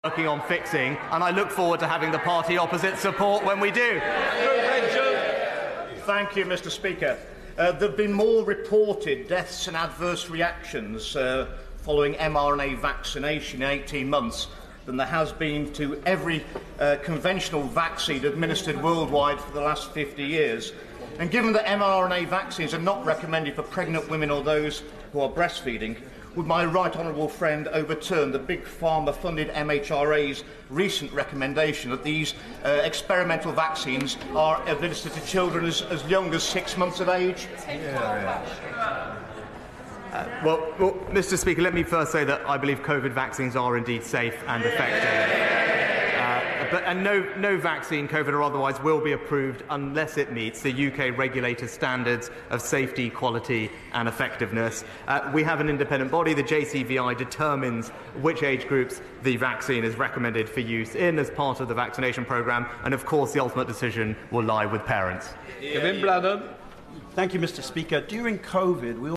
Prime Minister’s Questions, 7 Dec 2022